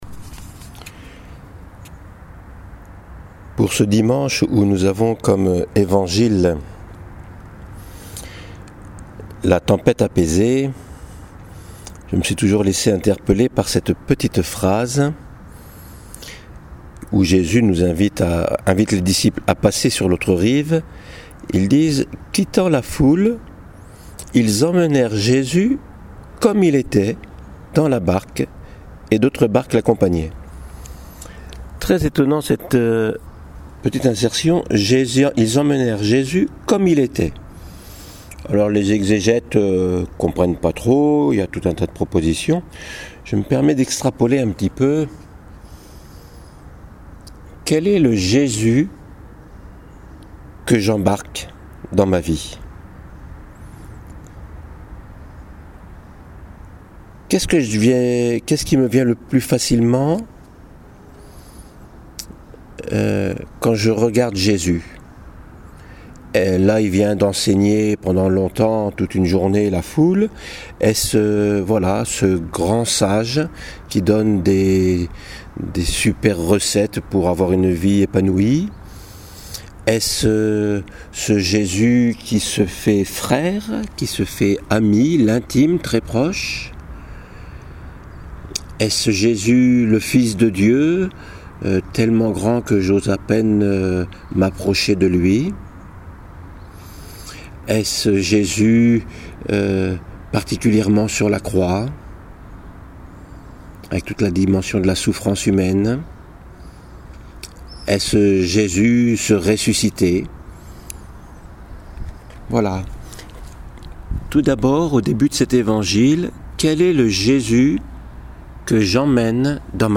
homélie du dimanche la tempête à passer - Oeuvre du Berceau